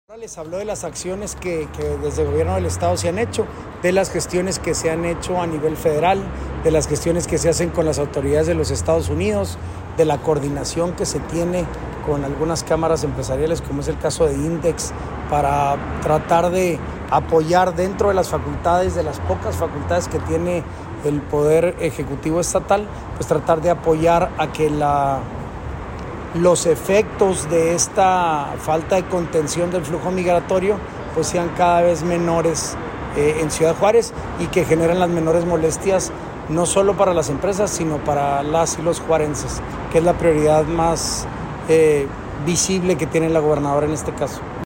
AUDIO: SANTIAGO DE LA PEÑA, SECRETARÍA GENERAL DE GOBIERNO (SGG)